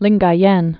(lĭnggä-yĕn)